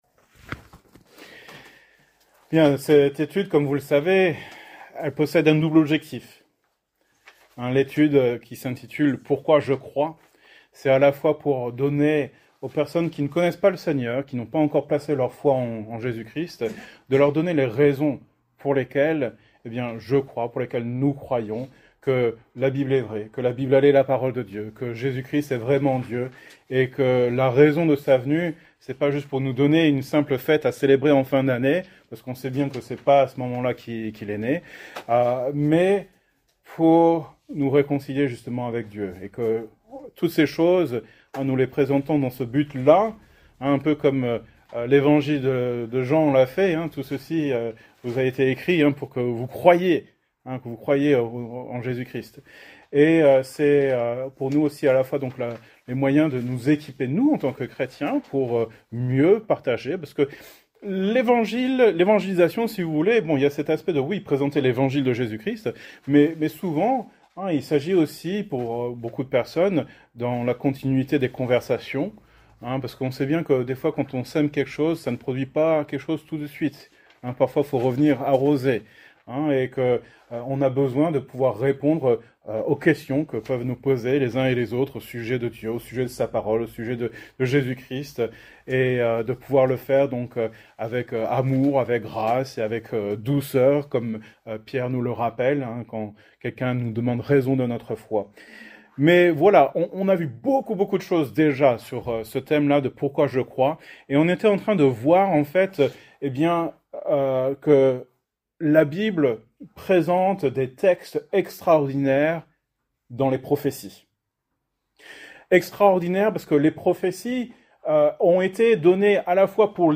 Genre: Etude Biblique